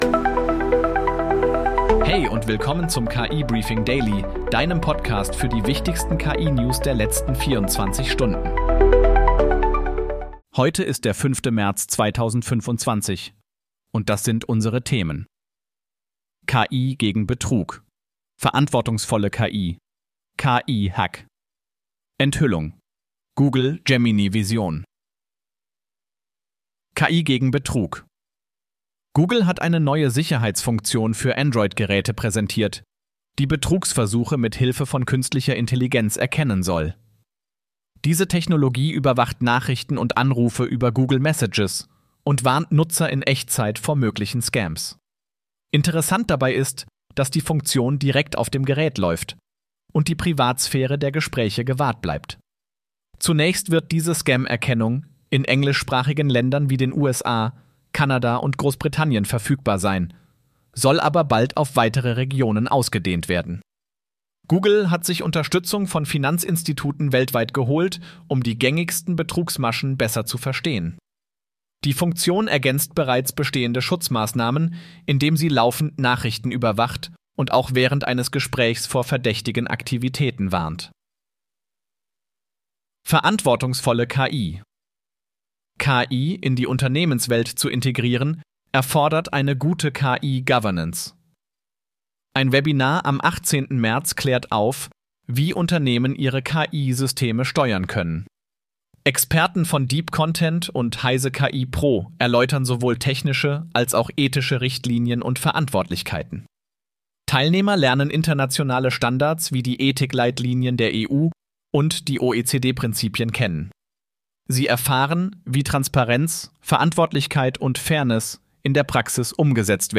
Möchtest du selbst einen solchen KI-generierten und 100% automatisierten Podcast zu deinem Thema haben?